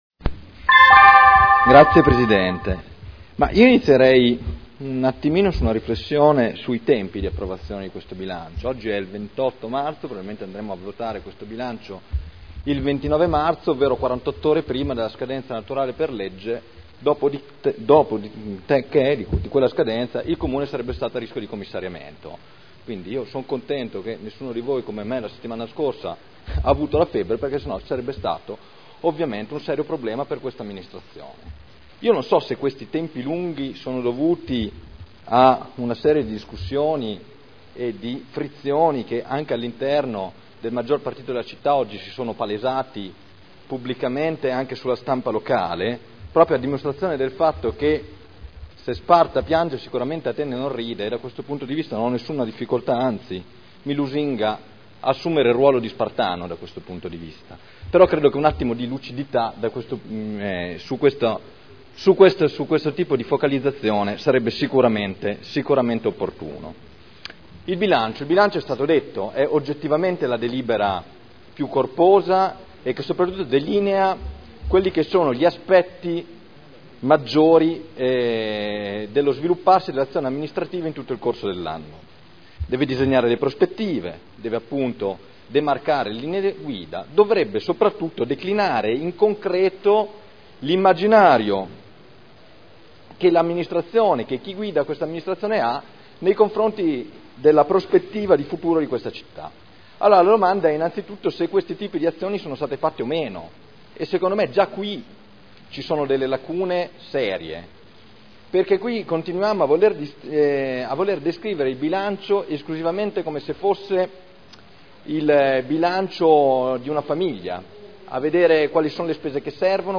Seduta del 28/03/2011. Dibattito sul Bilancio.